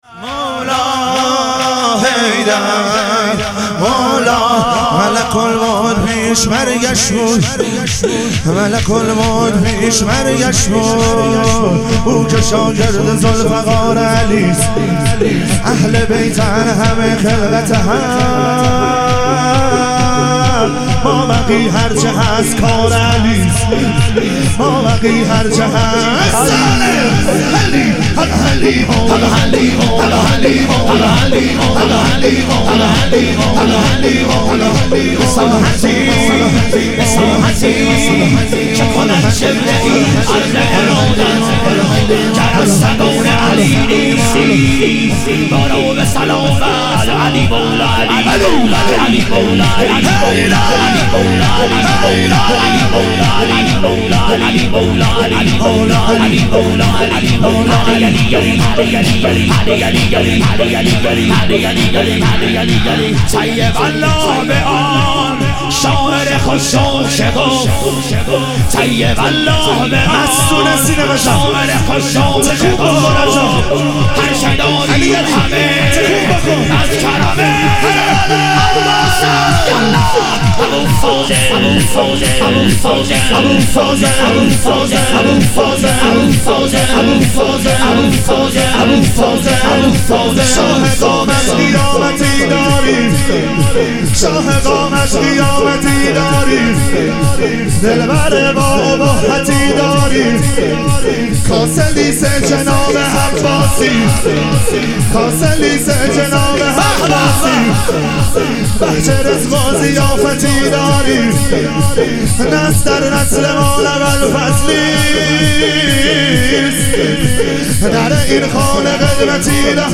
اربعین امام حسین علیه السلام - شور